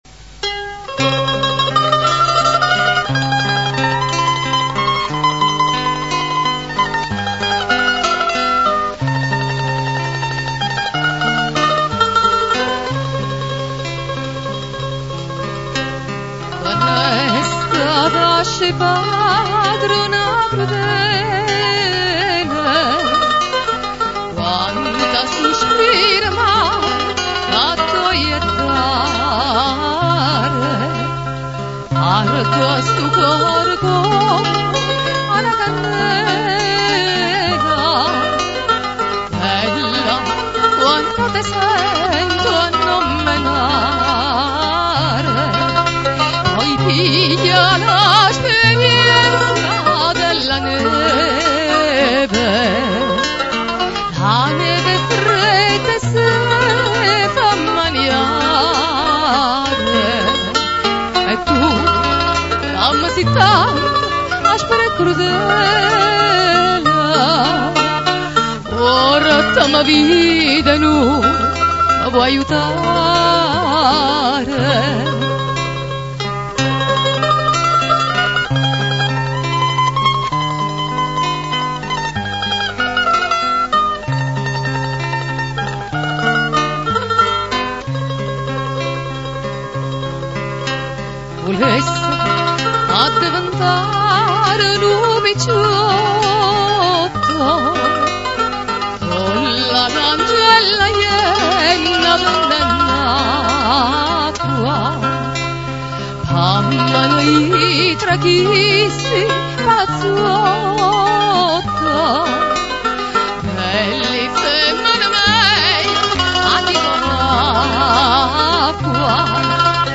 Ascolta Classiche vesuviane cantate da Napoli Antica Se non si ascolta subito il suono attendere qualche secondo, solo la prima volta